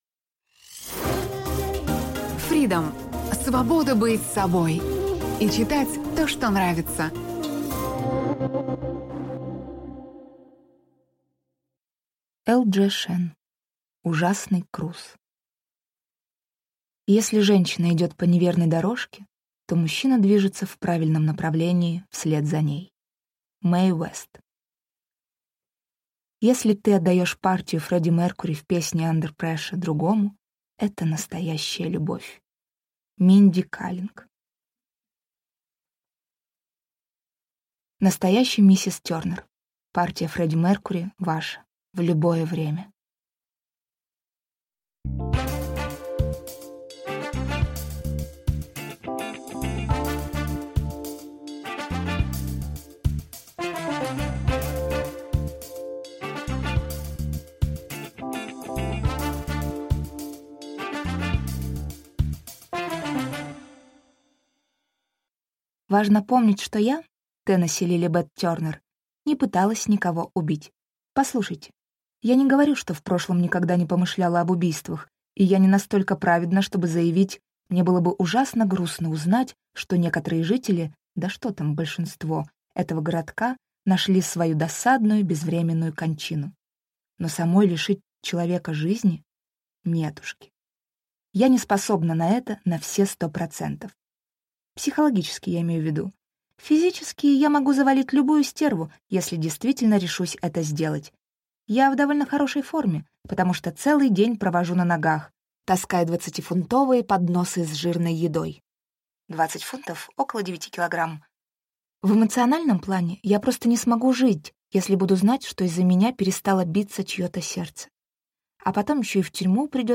Аудиокнига Ужасный Круз | Библиотека аудиокниг